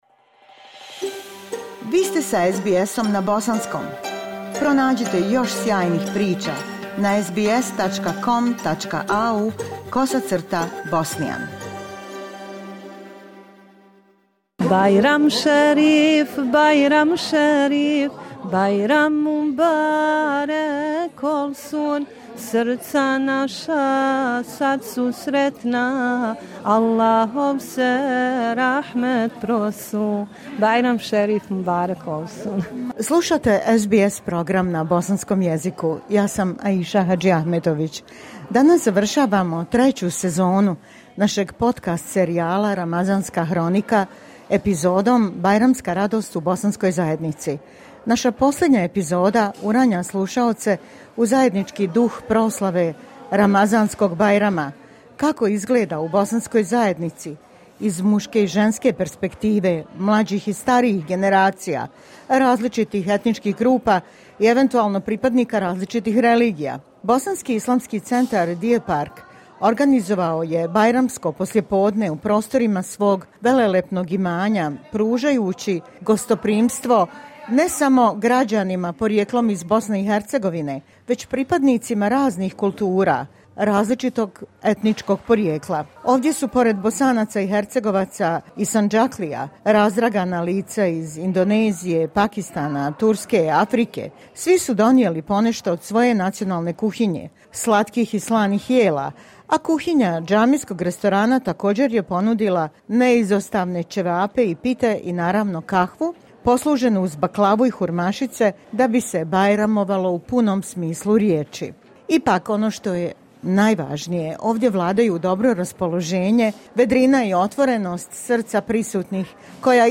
Završna epizoda treće sezone našeg podcast serijala "Ramazanska hronika" uranja slušaoce u u zajednički duh proslave Ramazanskog bajrama u bosanskohercegovačkoj zajednici ABIC Deer Park, koji je organizovao bajramski ručak za džematlije i podijelio bajramske paketiće djeci. Poslušajte kako izgleda Bajram u zajednici Bošnjaka iz muške i ženske perspektive, te mlađih i starijih generacija.
Atmosfera je bila ispunjena vedrinom, dobrim raspoloženjem i srdačnošću jer su svi željeli podijeliti jedni s drugima radost Bajrama.